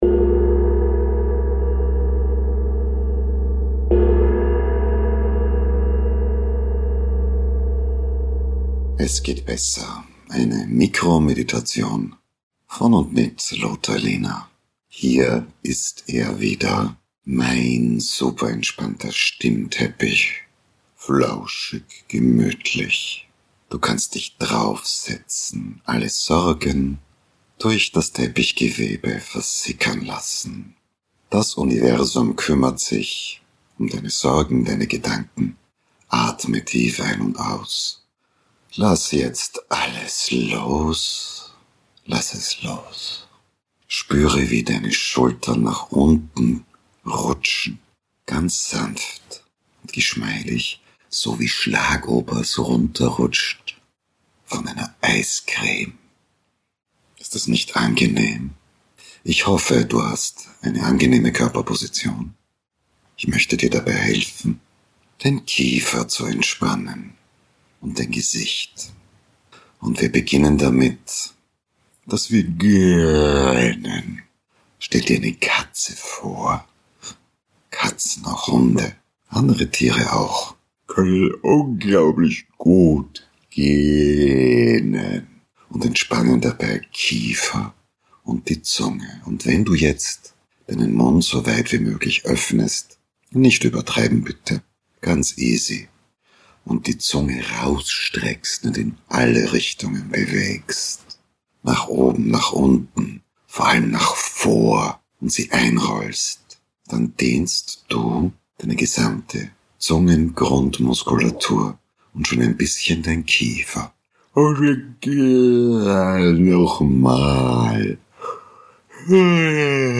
Die akustischen Glückskekse sind kurze, knackige Meditationen, die